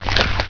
attackmunch.wav